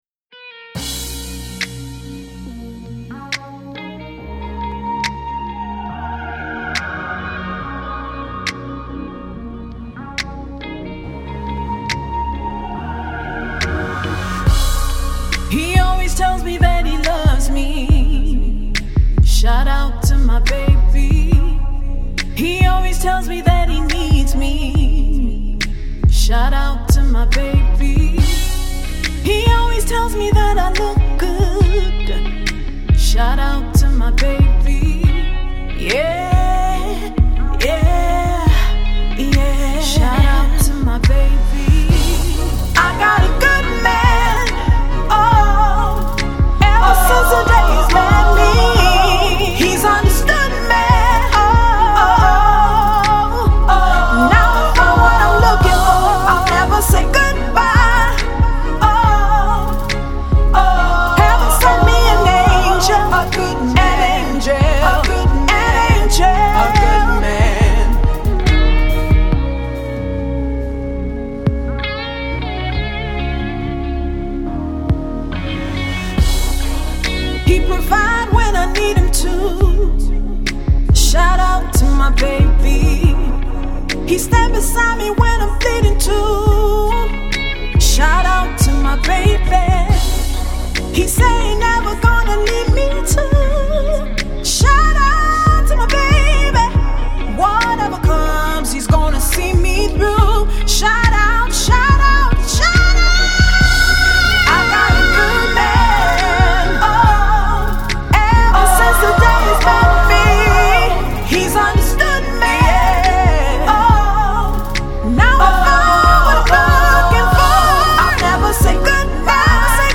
a blend of Afrosoul and new school R&B